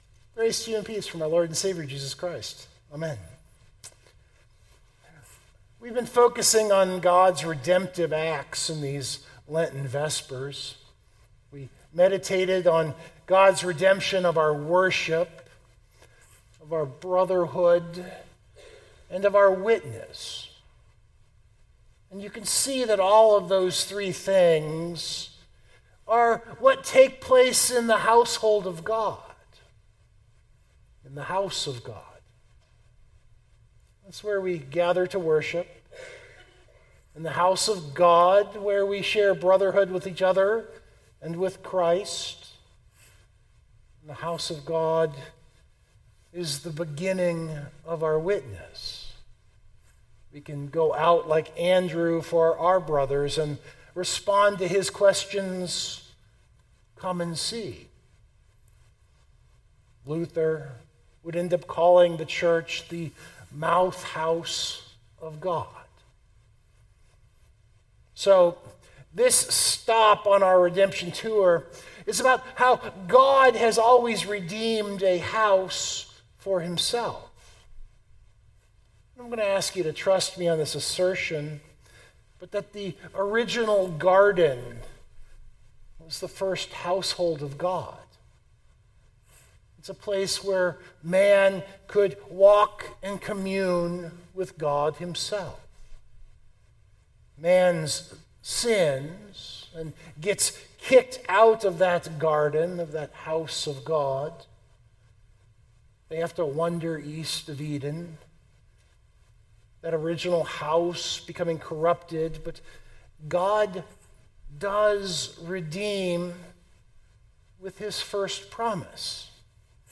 031826 Lent MW4 SermonDownload This is something of the end of the Redemption Tour.